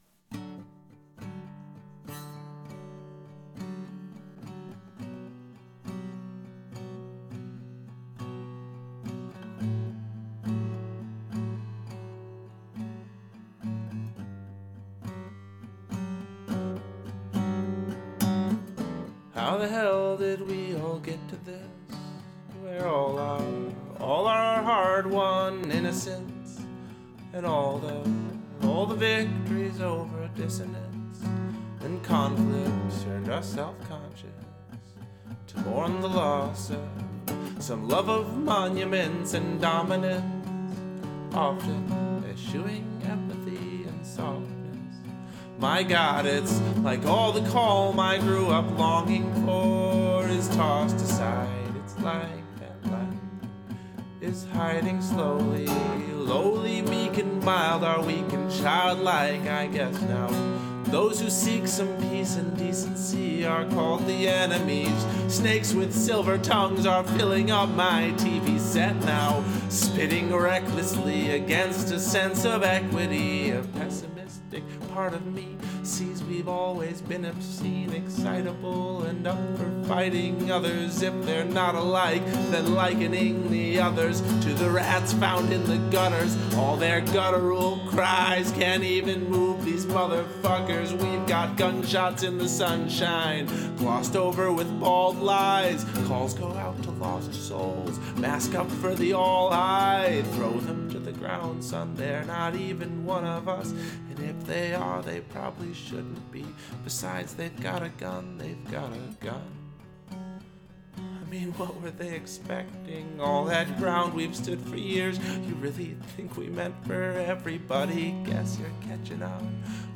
Beautiful chord change into the chorus.
This descending bass line and gently crunchy chords gives the feeling of a lament and sadness.